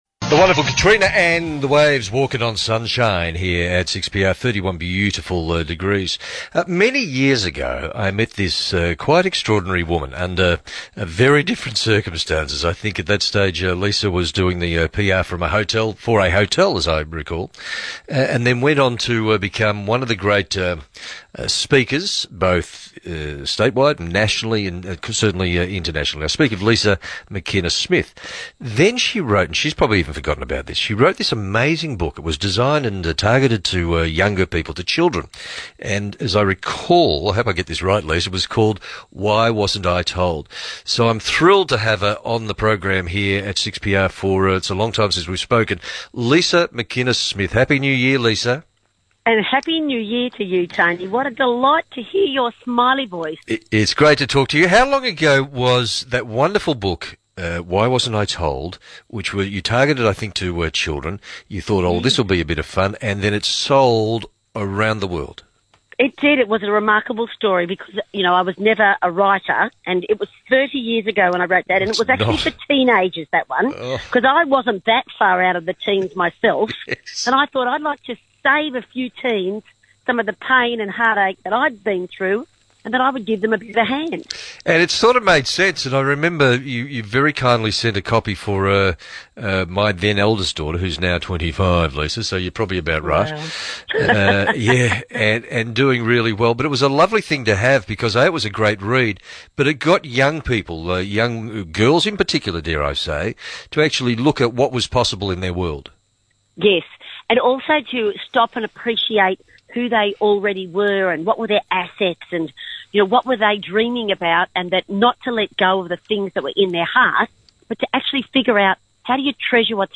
I hope this 12 minute interview will inspire you to make great choices that will impact what your 2016 will look like!